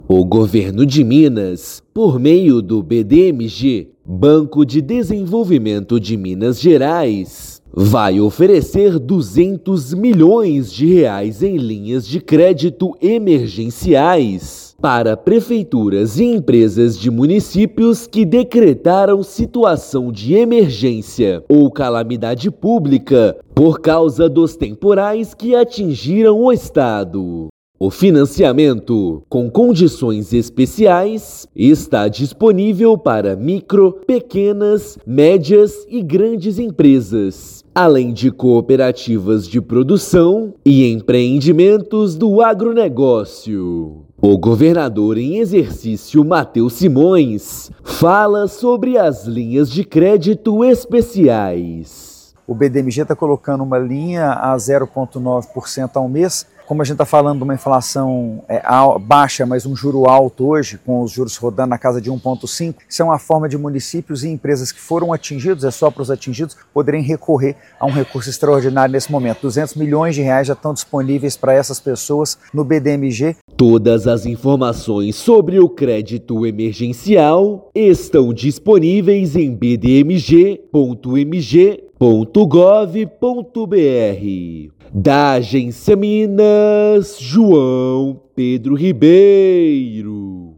BDMG vai disponibilizar linhas de financiamento com condições especiais para apoiar empresários e municípios mineiros com decreto de calamidade. Ouça matéria de rádio.